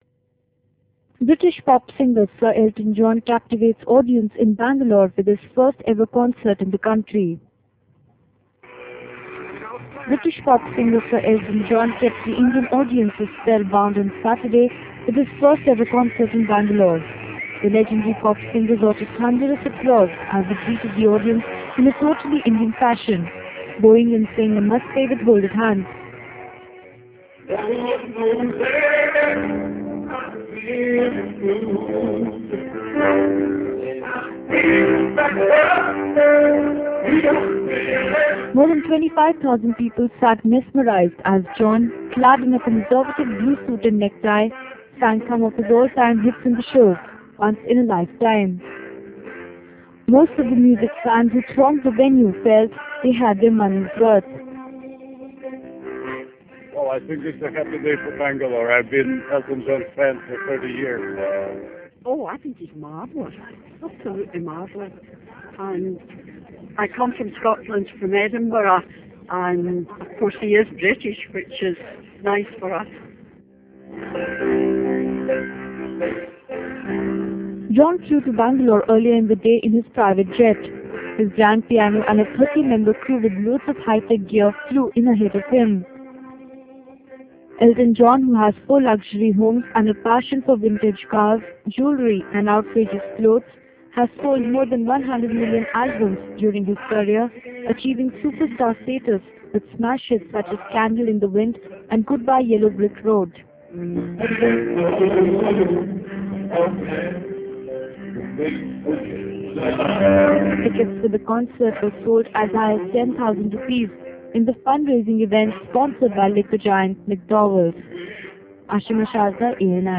during a performance at a show in Bangalore